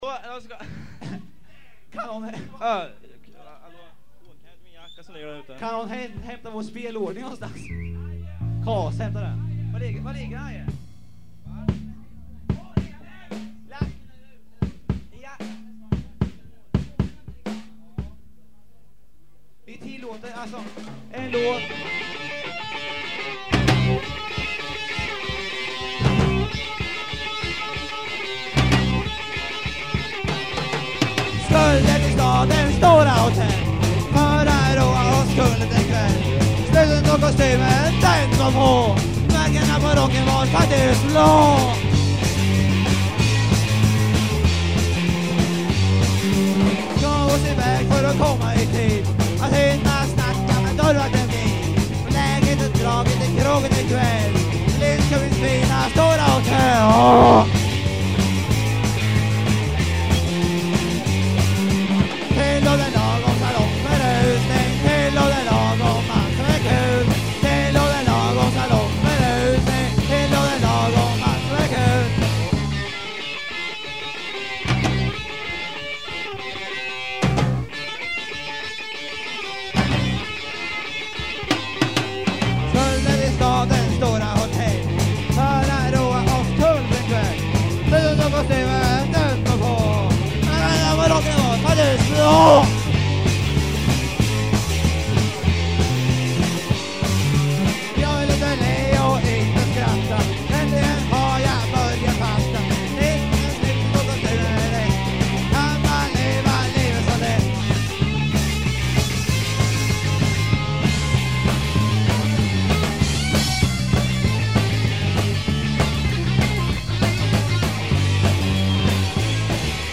Guitar
Bass
Drums
Voice
Live at Kannan 1980